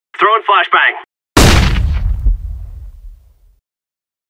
Play, download and share flesh beng original sound button!!!!
throwing_flashbag.mp3